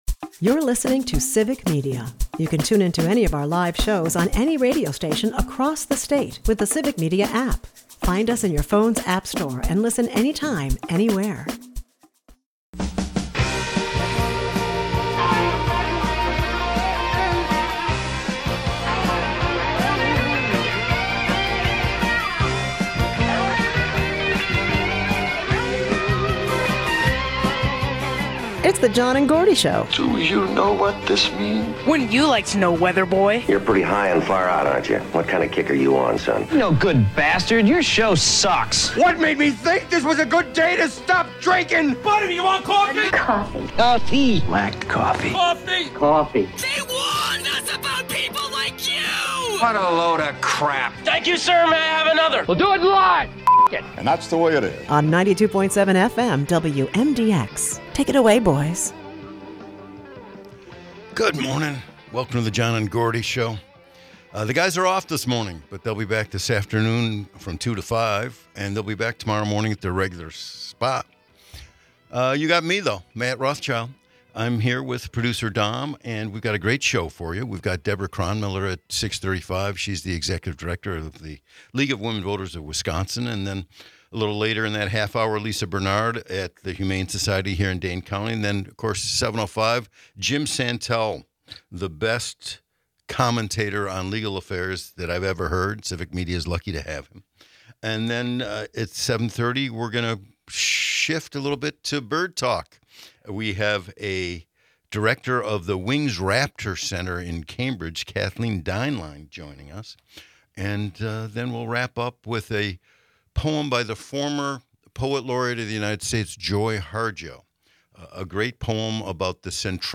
Birdwatching and hunting tales pepper the episode, with listeners chiming in about their wildlife sightings. It's a rich tapestry of local culture, civic engagement, and the simple joys of nature.